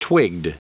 Prononciation du mot twigged en anglais (fichier audio)
Prononciation du mot : twigged